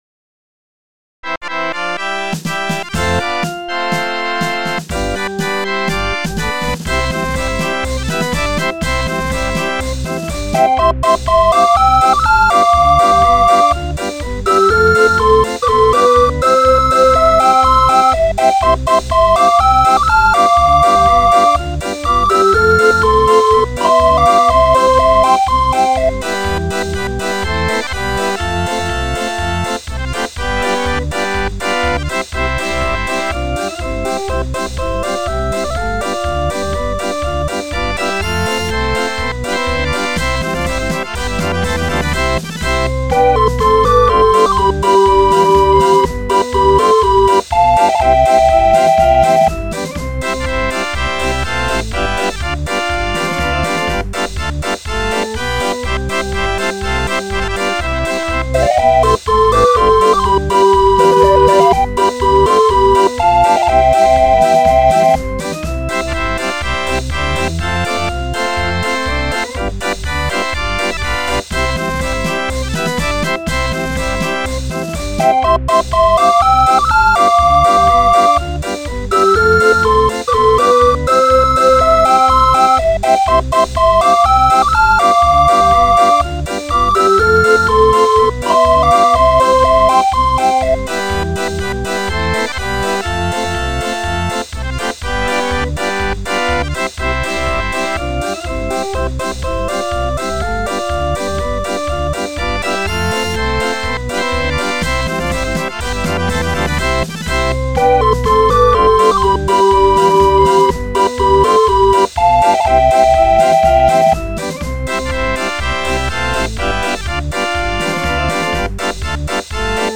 Musikrollen, Notenbücher und Zubehör für Drehorgeln.